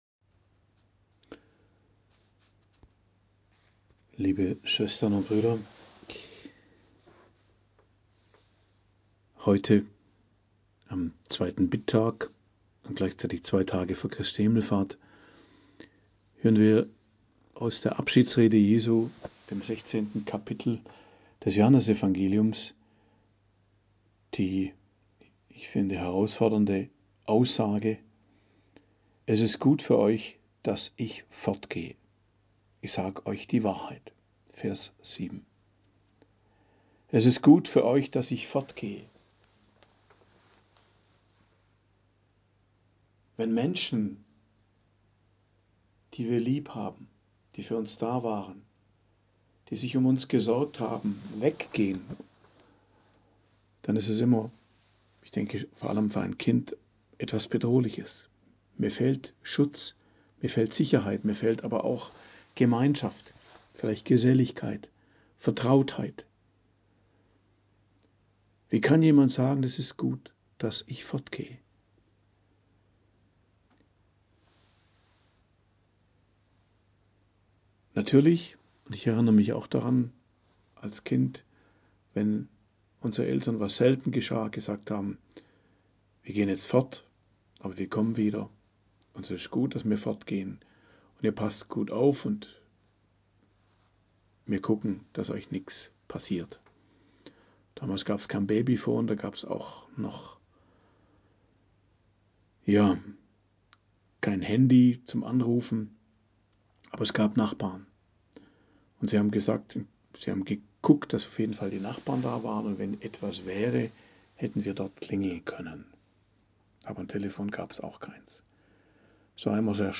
Predigt am Dienstag der 6. Osterwoche, 7.05.2024 ~ Geistliches Zentrum Kloster Heiligkreuztal Podcast